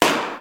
Arcade - Taiko no Tatsujin 2020 Version - Common Sound Effects
Balloon.wav